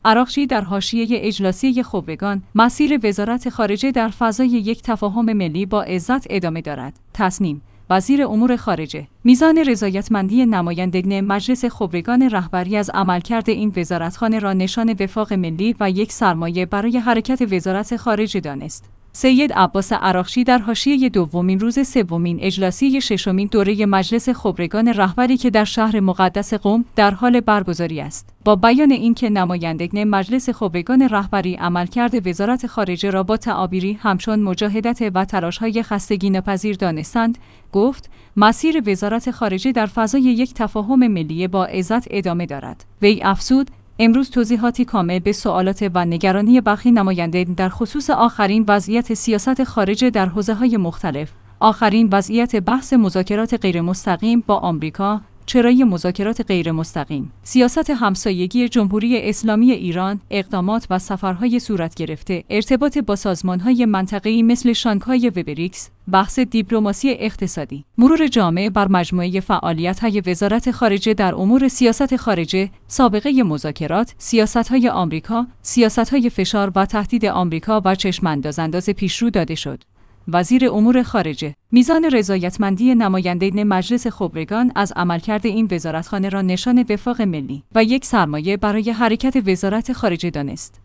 عراقچی در حاشیه اجلاسیه خبرگان: مسیر وزارت خارجه در فضای یک تفاهم ملی با عزت ادامه دارد